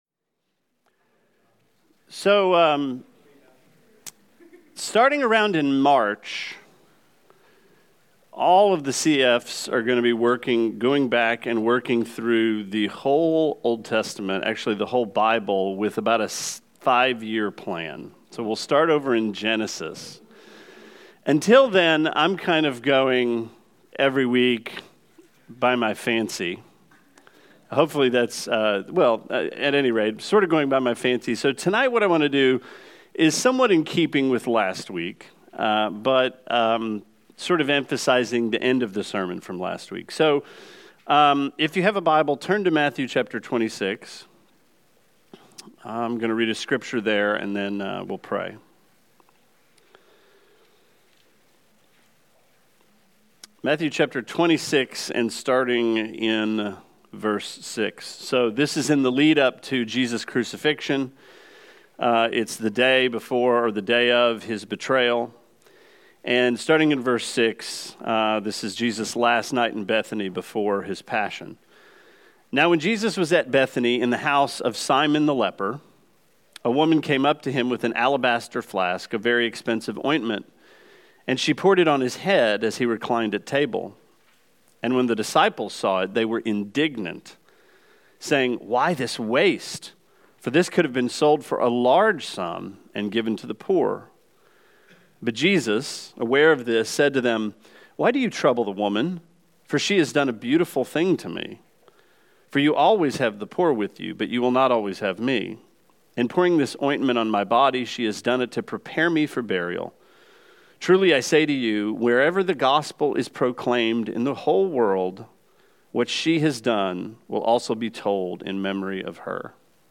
Sermon 02/08: Good Works